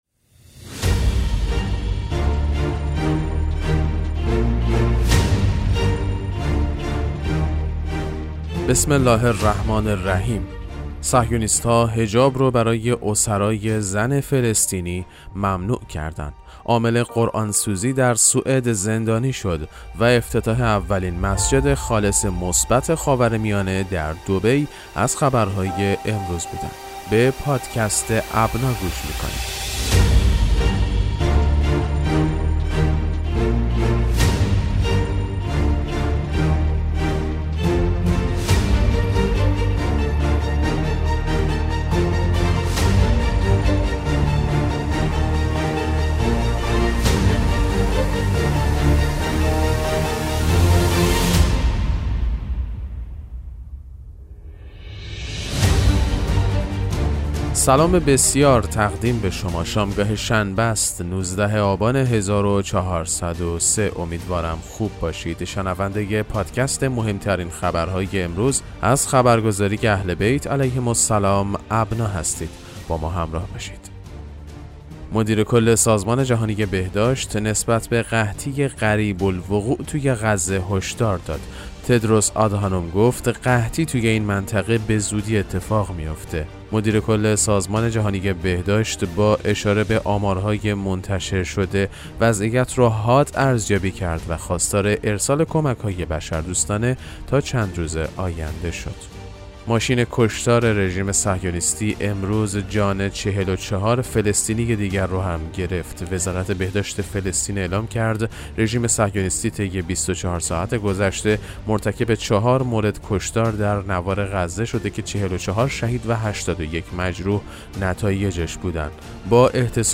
پادکست مهم‌ترین اخبار ابنا فارسی ــ 19 آبان 1403